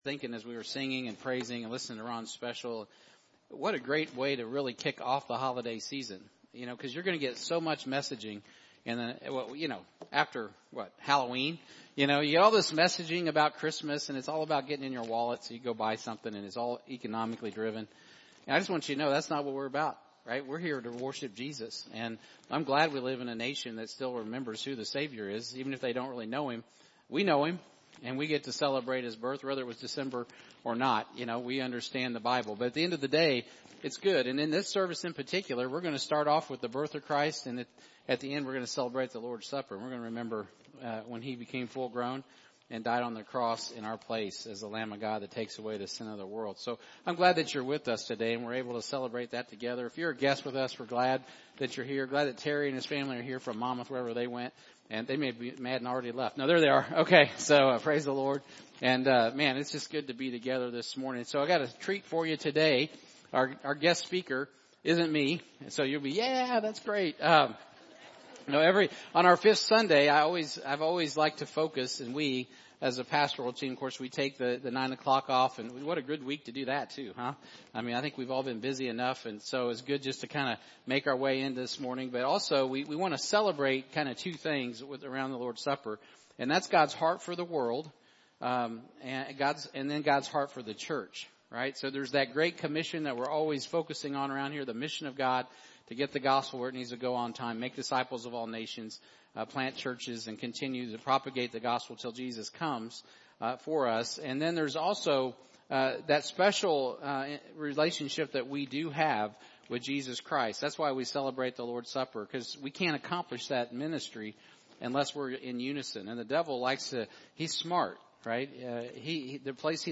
- YouTube Podcasts Find a link here to subscribe to any or all of the Podcasts avaiable Sunday Morning Guest Speakers Current Sermon Is it Worth It?